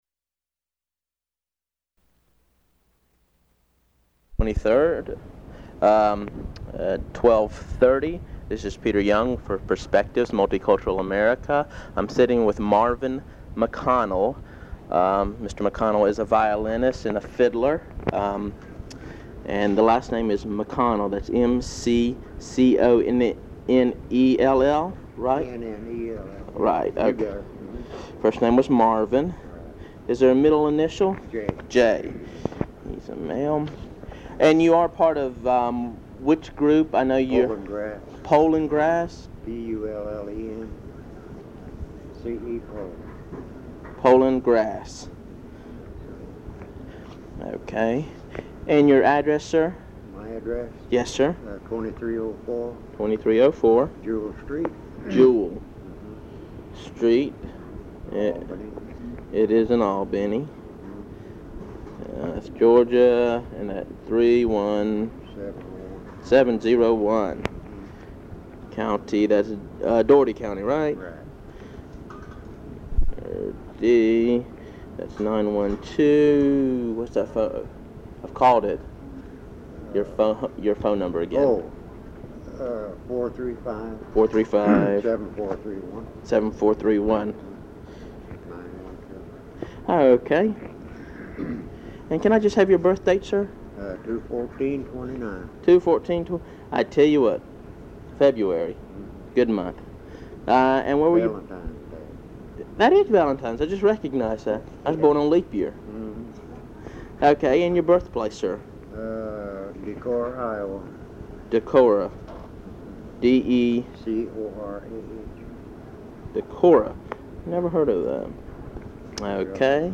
Audio file digitized from cassette tape. Part of the South Georgia Folklife Project at Valdosta State University Archives and Special Collections. Topics include music, bluegrass, fiddles, and the Agrirama.